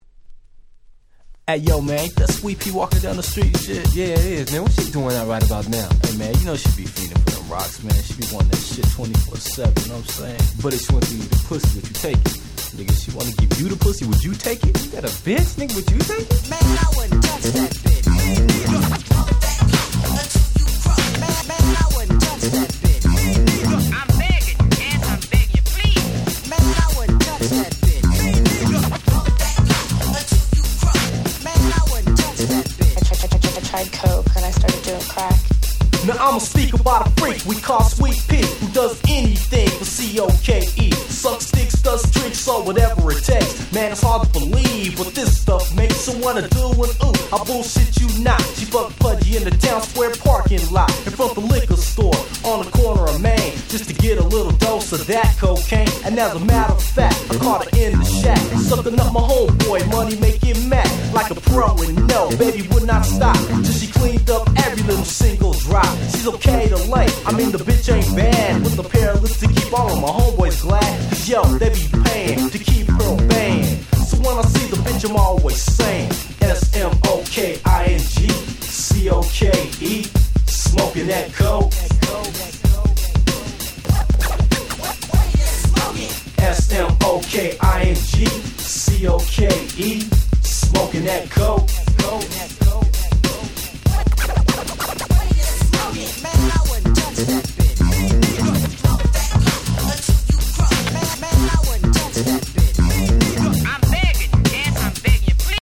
90' Nice Hip Hop !!
ゴーゴー ツイスト 90's Boom Bap ブーンバップ